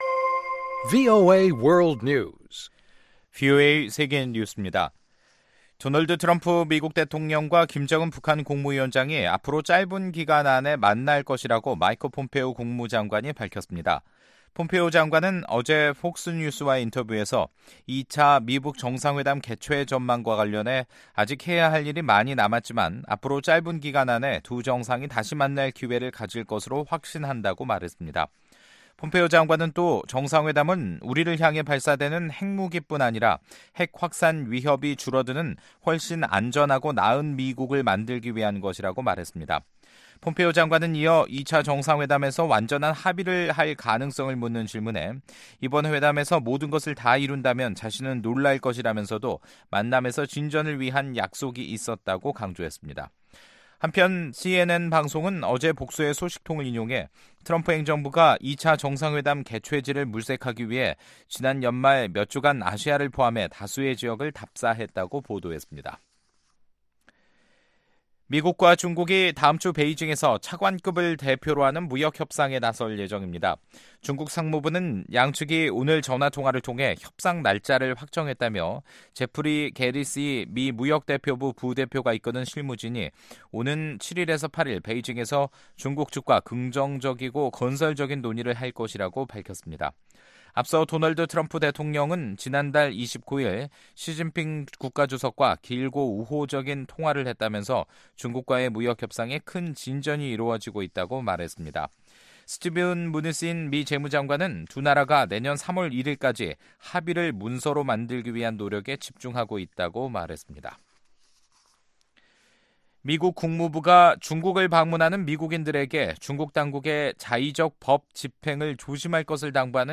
VOA 한국어 간판 뉴스 프로그램 '뉴스 투데이', 2019년 1월 4일 2부 방송입니다. 3일 개원한 미국 의회에서 의원들은 북한 문제에 대해 외교를 통한 해결을 지지한다면서도 북한이 구체적인 비핵화 조치를 취할 때까지 제재는 계속돼야 한다는 초당적인 입장을 보였습니다. 미국의 전직 관리들은 이탈리아 주재 북한 외교관의 잠적 소식과 관련, 정권의 안정성 여부와 성급히 결론 짓기 어렵다는 신중한 반응을 보였습니다.